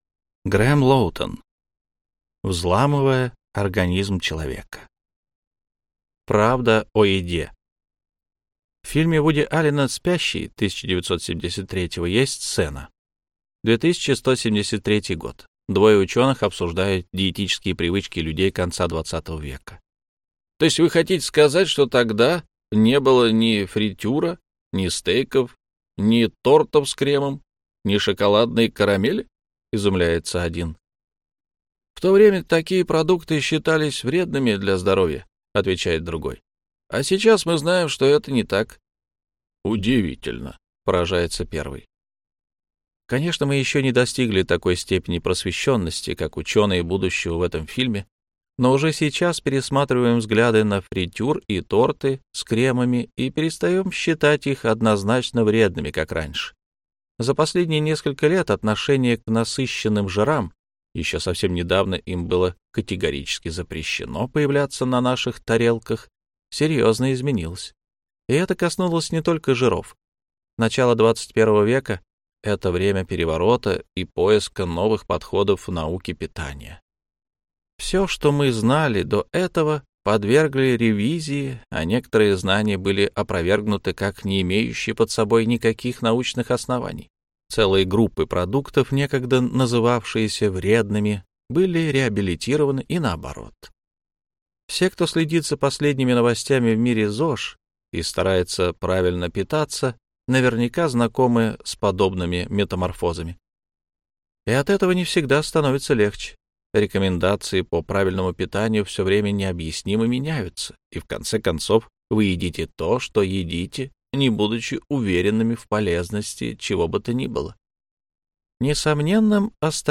Аудиокнига Взламывая организм человека | Библиотека аудиокниг